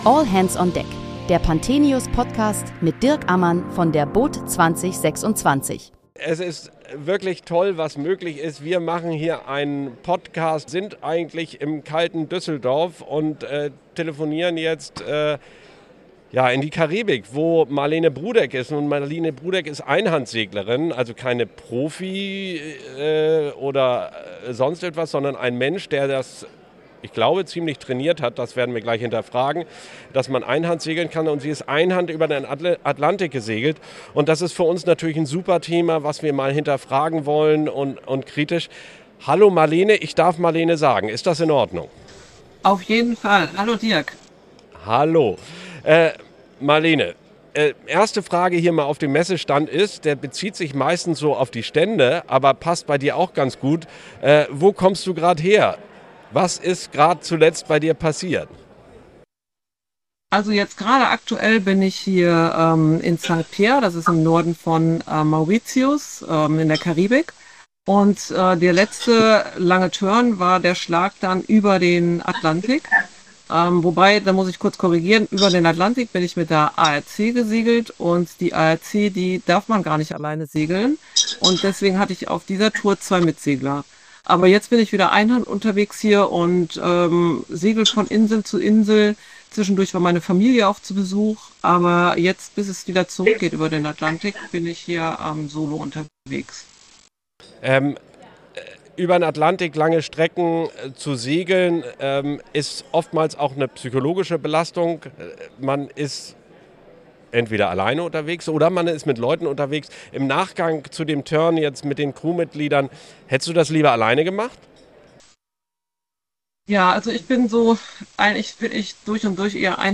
Während der boot Düsseldorf 2026 haben wir täglich mit Gästen aus der Branche über aktuelle und kontroverse Themen rund um den Wassersport gesprochen.